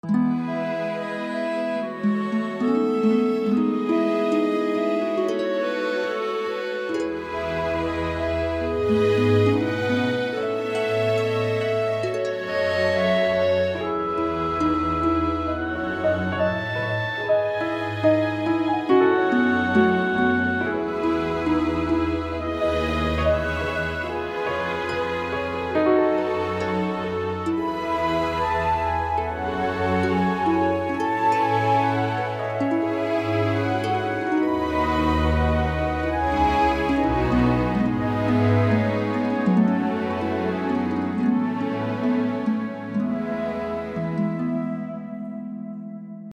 Filmmusik/Soundtrack - finale Version?
Ich habe den neuen B-Teil überarbeitet (auch Noten der Harfe überarbeitet) und Dynamik, Expression und Vibrato optimiert.
Ich habe die BPM um 20 reduziert.
Das klang irgendwie nicht gut für mich, also habe ich ihn komplett umgebaut, teilweise mit anderen und neuen Instrumenten (Staccato Cello/Klavier).
Der Anfang ist noch fröhlich, dann wird es bedrohlicher.